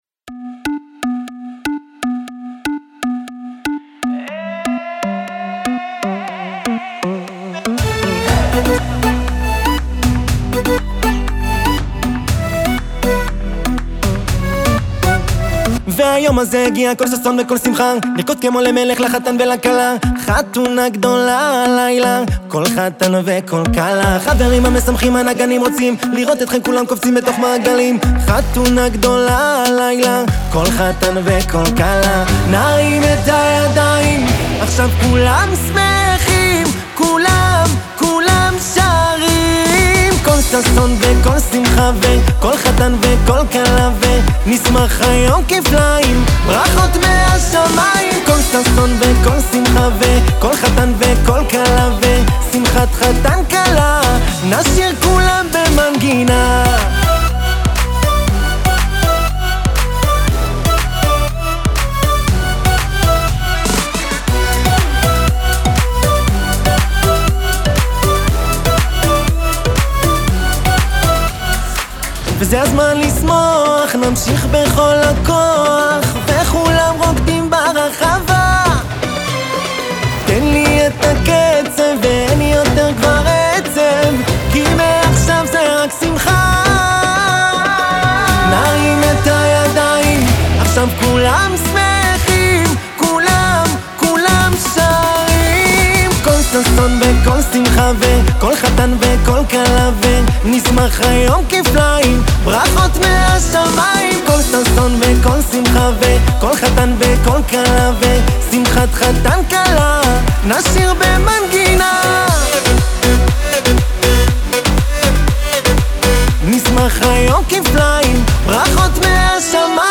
עם ביט עכשווי ועדכני שעתיד להפוך להמנון החתונות הבא.